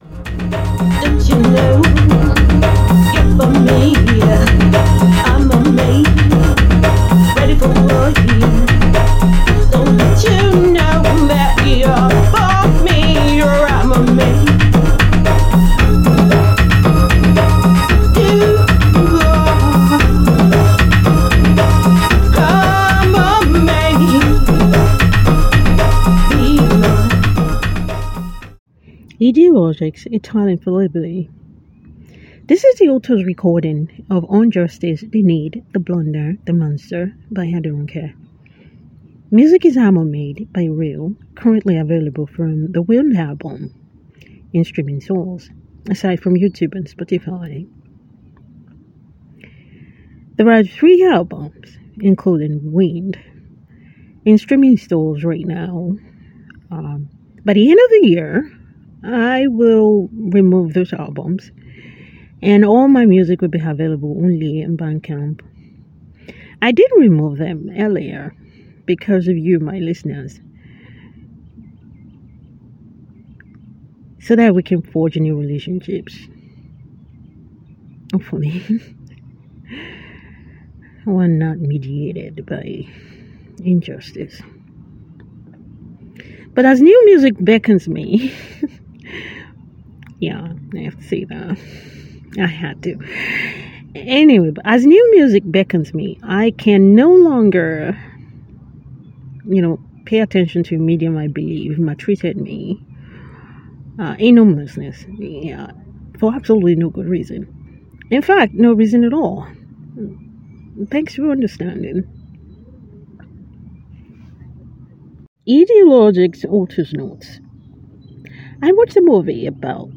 On justice, audio narration
My readers, my voice narration of On justice: the need, the blinder, the monster.